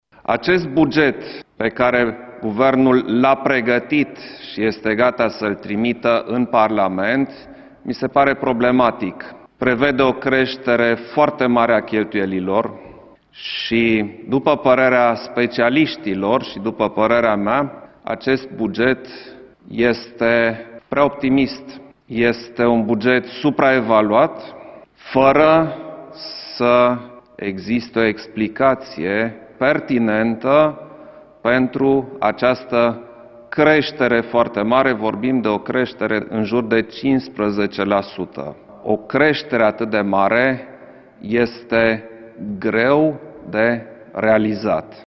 Șeful statului a criticat proiectul de buget pregătit de Guvern şi care ar urma să fie trimis Parlamentului. Potrivit lui Iohannis, proiectul este unul problematic, care prevede o creştere foarte mare a cheltuielilor, creștere pentru care nu există o explicație pertinentă: